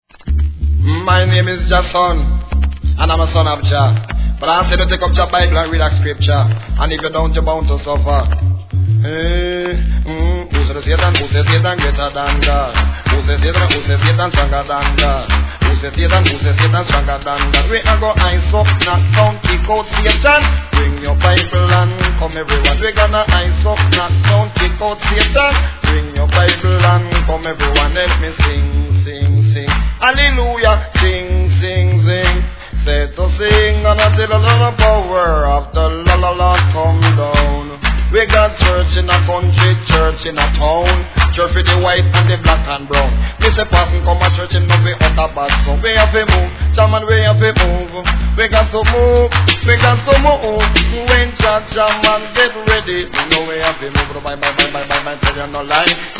REGGAE
1987年、NICE DeeJay!!